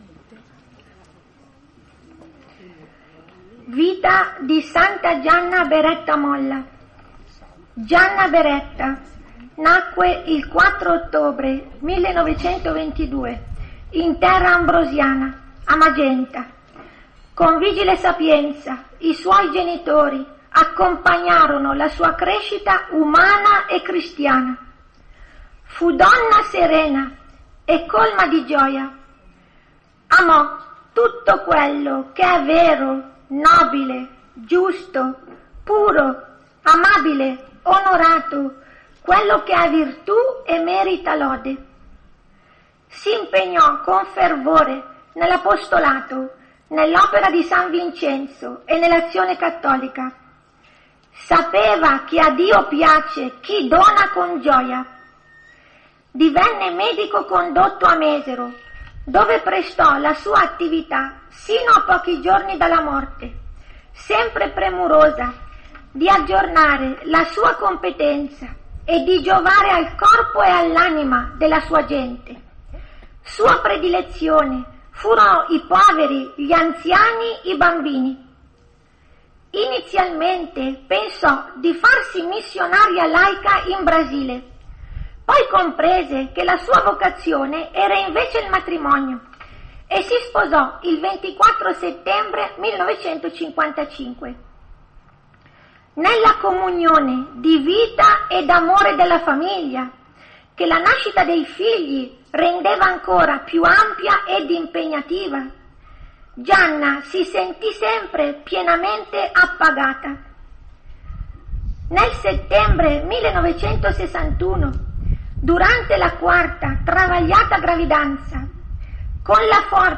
28 aprile Festa liturgica S. Gianna